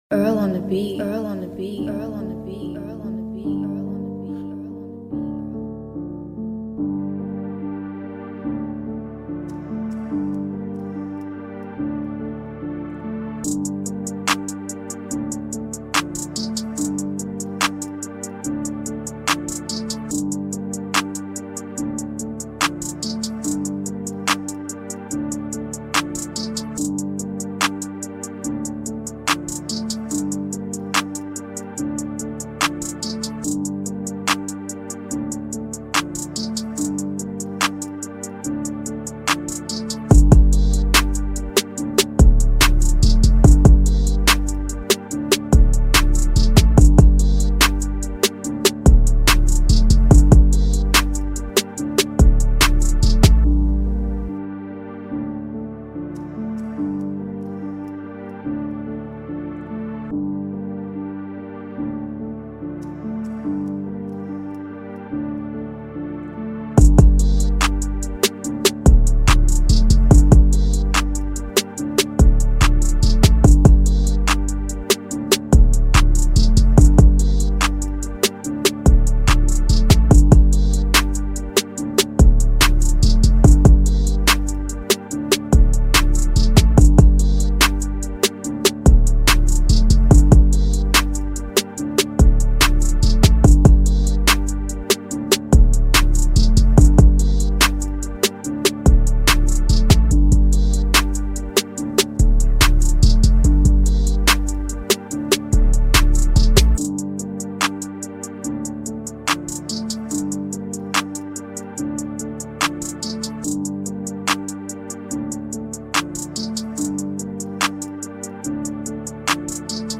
Here's the instrumental version